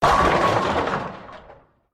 Plants vs. Zombies sounds (звуки из игры) Часть 1
bowlingimpact2.mp3